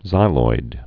(zīloid)